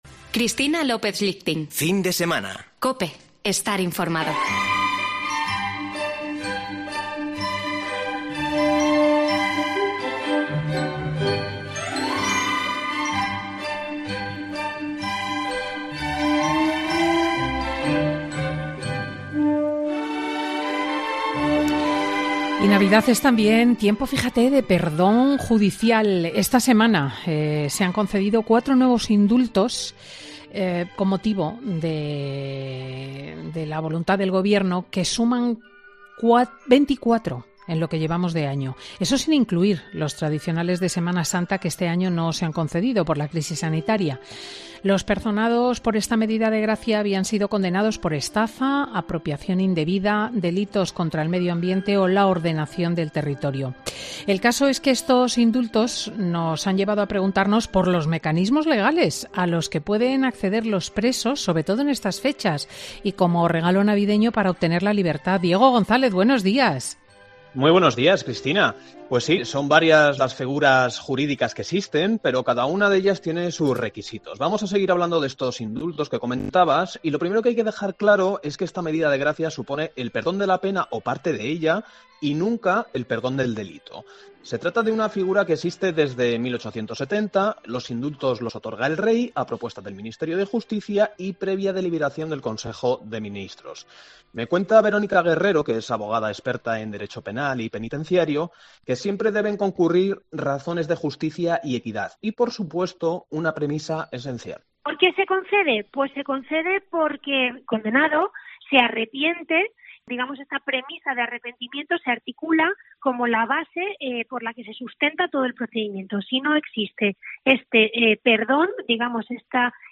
El famoso juez cuenta en Fin de Semana con Cristina cómo después de cumplir determinados requisitos, los presos pueden tener una reducción de pena